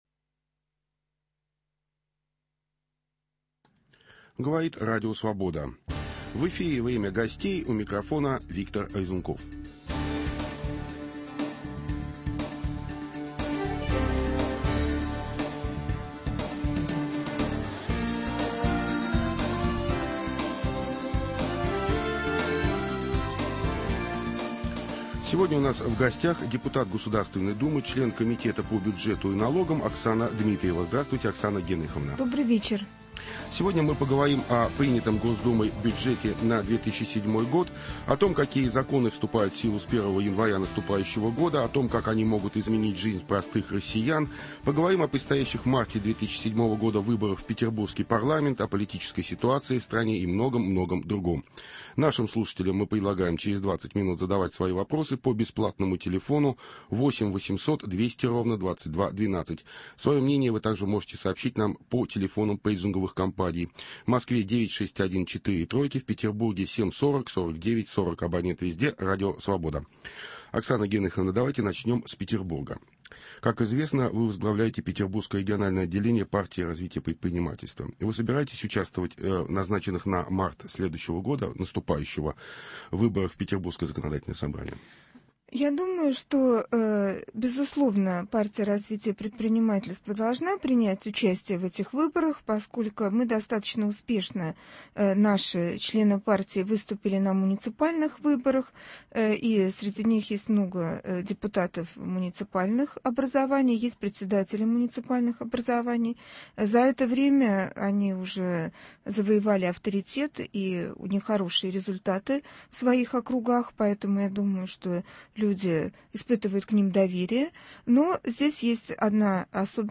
В чем заключается коррупционность и порочность системы распределения льготных лекарств? Об этом и многом другом - в беседе с депутатом Государственной думы Оксаной Дмитриевой.